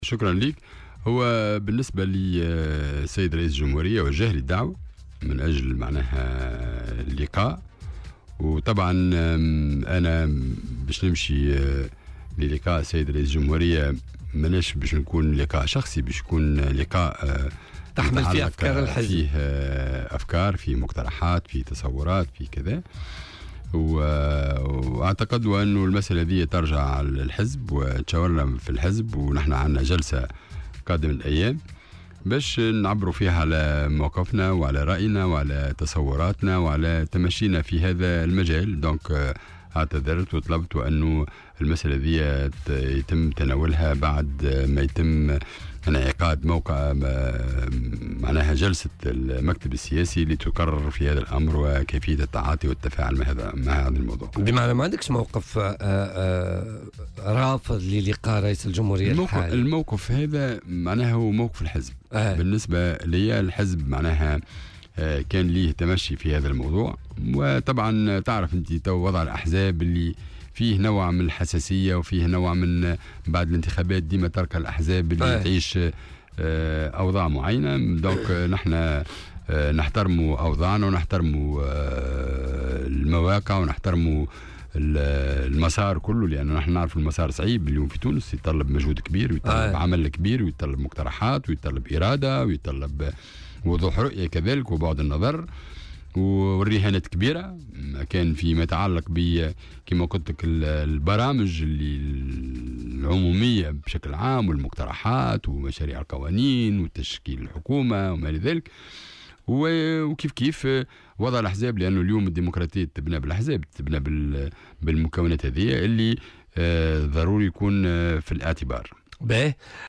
وأوضح ضيف "بوليتيكا" على "الجوهرة أف أم" أن رئيس الجمهورية وجّه له دعوة لكنه اعتذر لأن المسألة تتعلّق بقرار سيتخذه لاحقا مجلس مكتب حزبه السياسي والذي سينعقد للتطرّق لعدة نقاط منها المشاورات بخصوص تشكيل الحكومة، وفق تعبيره، مؤكدا أن الحزب هو من سيحدد موقفه بخصوص هذه المسألة .